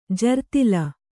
♪ jartila